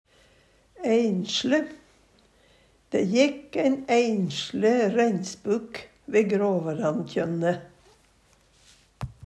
æinsle - Numedalsmål (en-US)